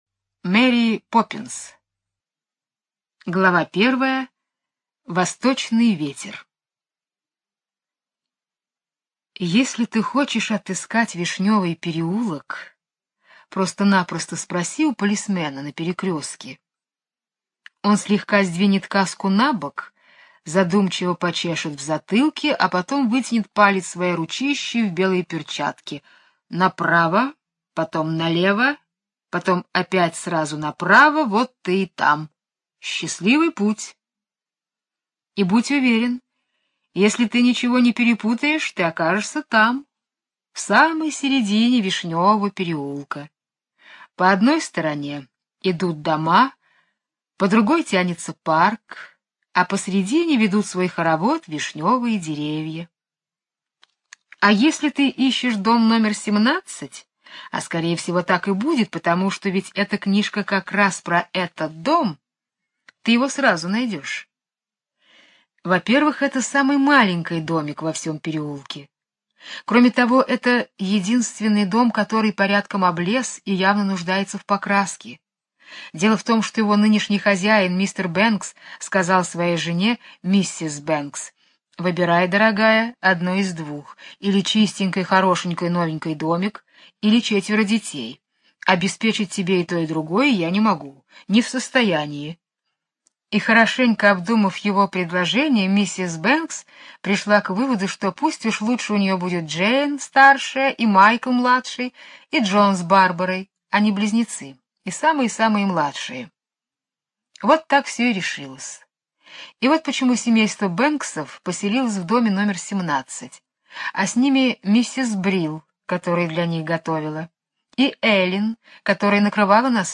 Аудиосказка Мэри Поппинс - слушать сказку онлайн